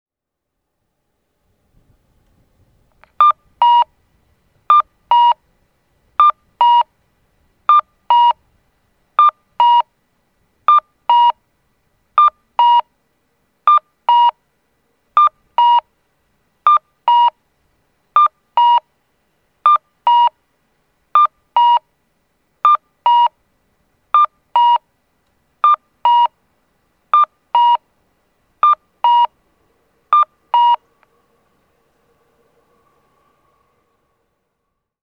交通信号オンライン｜音響信号を録る旅｜三重県の音響信号｜[鳥羽:013]近鉄鳥羽駅南交差点
近鉄鳥羽駅南交差点(三重県鳥羽市)の音響信号を紹介しています。